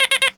beep_funny_scanning_targeting_01.wav